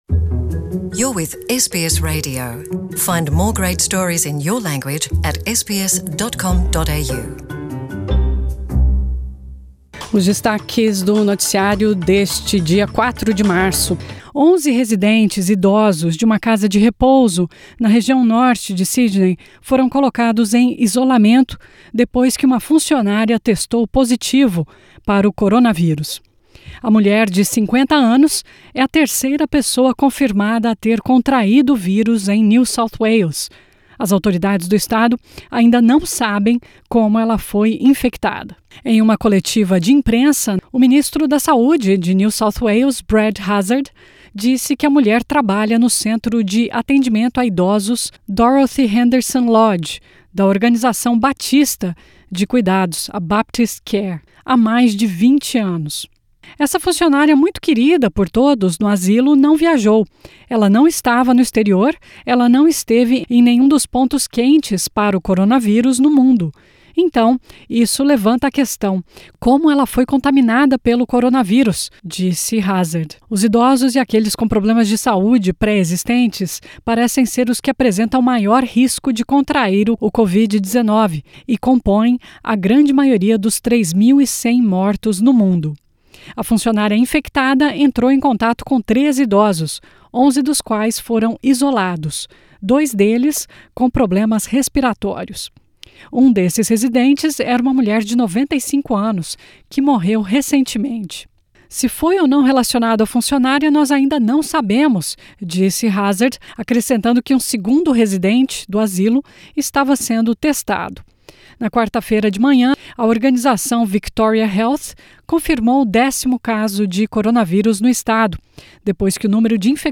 Onze idosos em isolamento depois que cuidadora testa positivo para coronavírus em Sydney. Ouça os destaques do noticiário desta quarta-feira 4 de março.